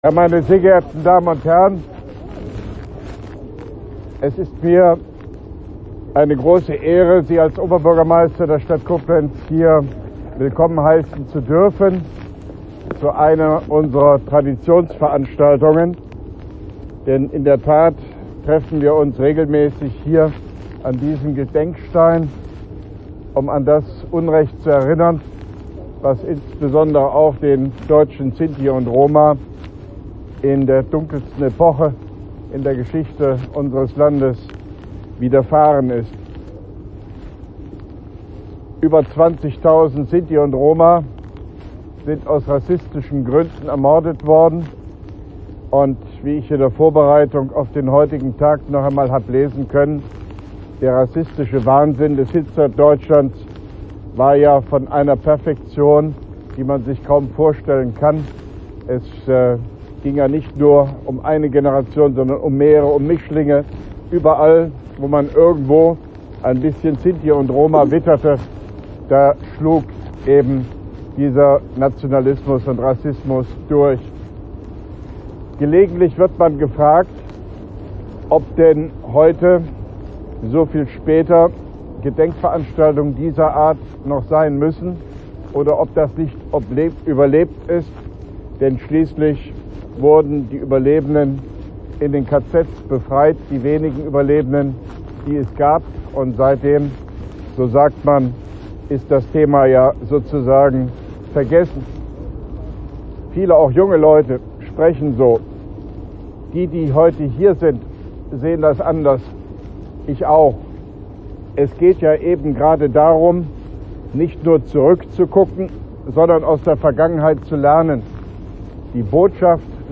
Ausführungen von OB Hofmann-Göttig vor der Kranzniederlegung am Gedenkstein für die ermordeten Sinti und Roma anlässlich des 75. Jahrestags des “Auschwitz-Erlasses”, Koblenz 17.12.2017